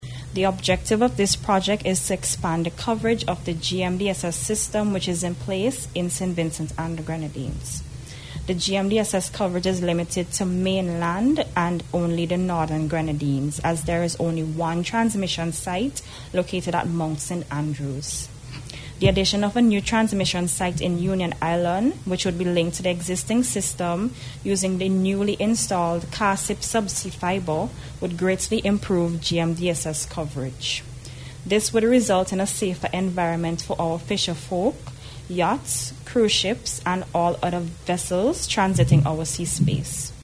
The signing was done during an official ceremony held here on Tuesday